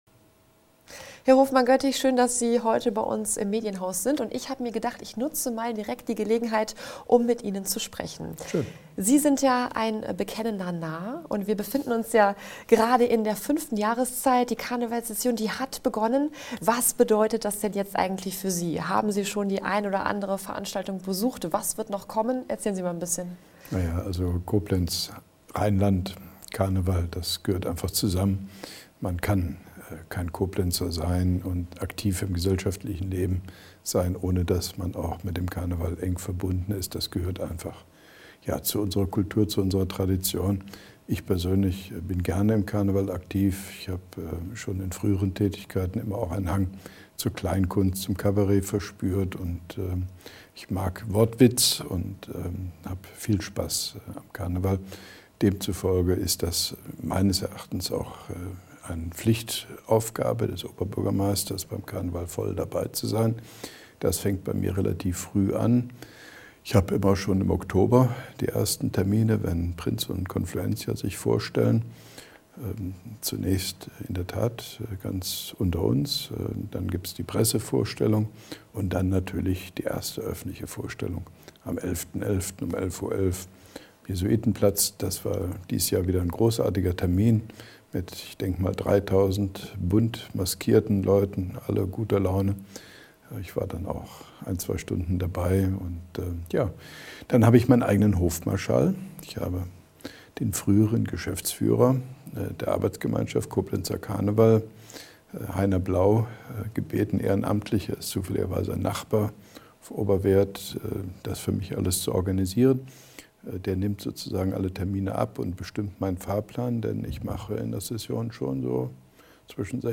Interview mit OB Hofmann-Göttig zu aktuellen Koblenzer Themen 2014/2015